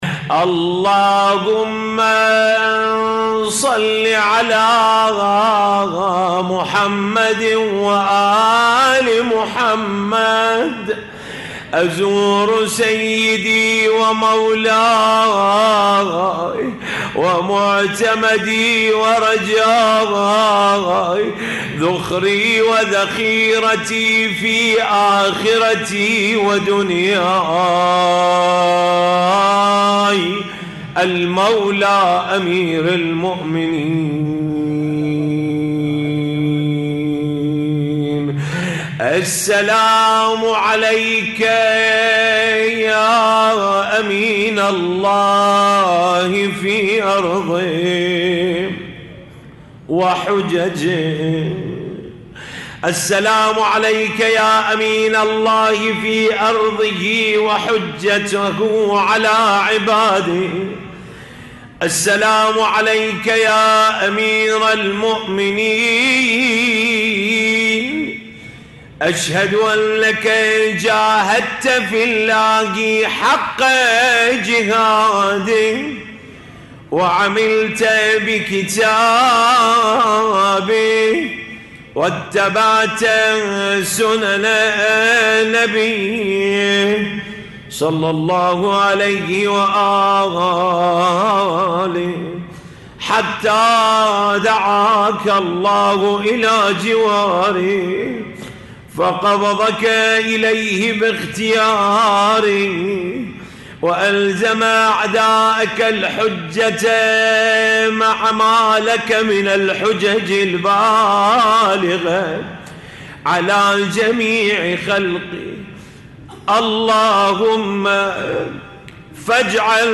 الرادود